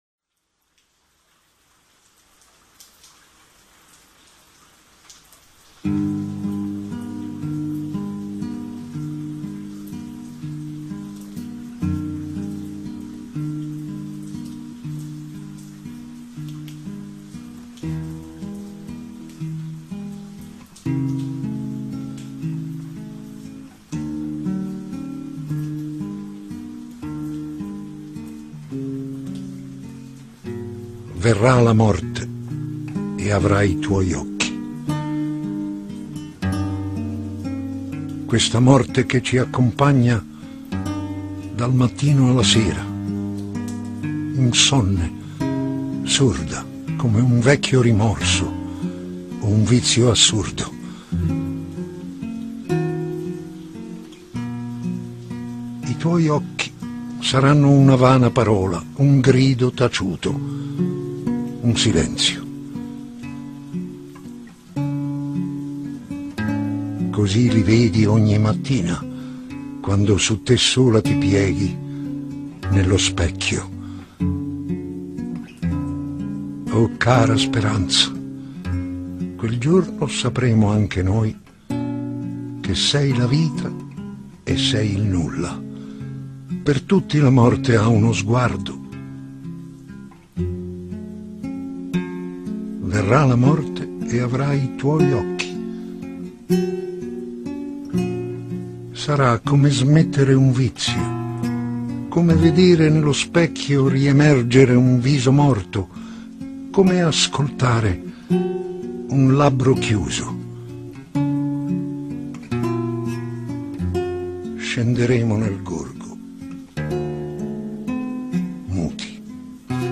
VITTORIO GASMAN legge PAVESE